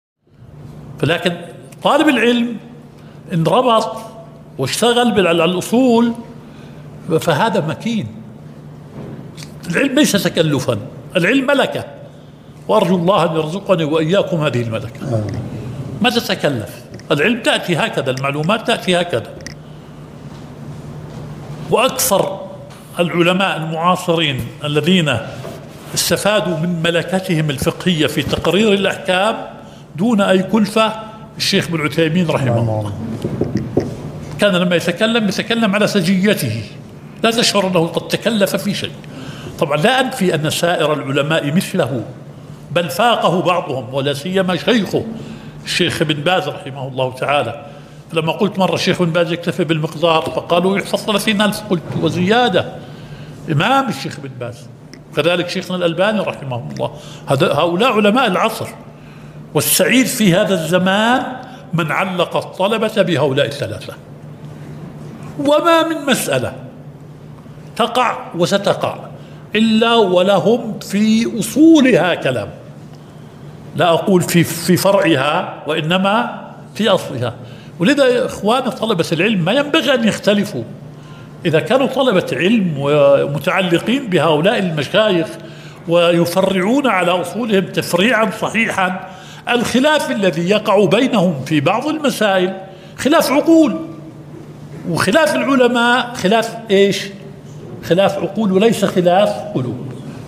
الدورة العلمية السابعة والعشرون